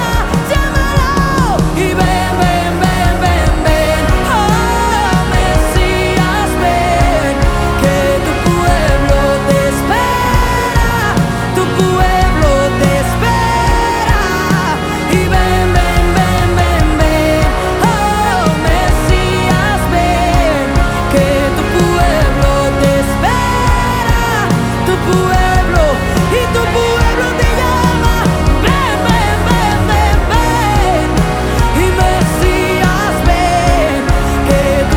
# Christian & Gospel